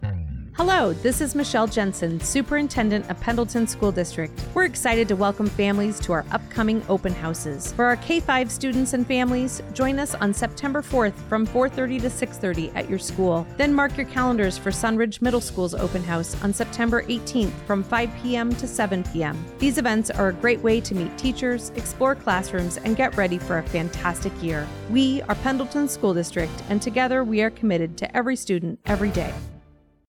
Latest Radio Spot